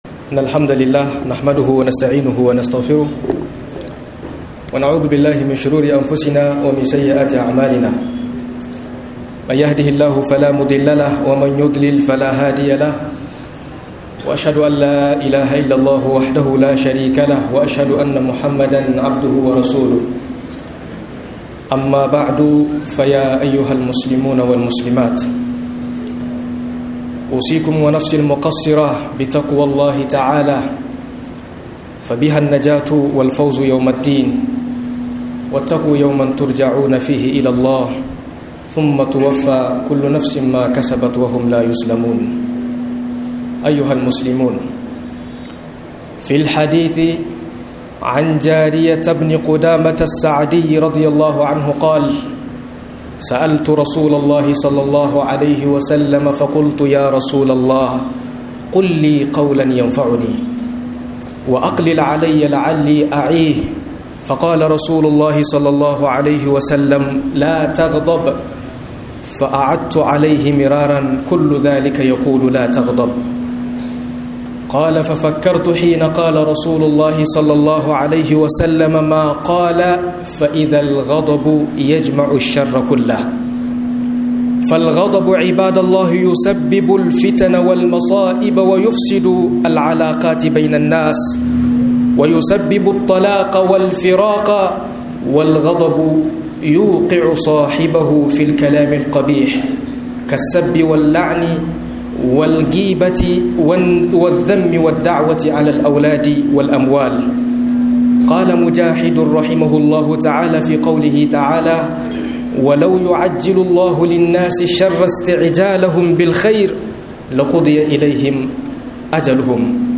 Mastalolin Huchi Da Hanyoyin Magance Chi - MUHADARA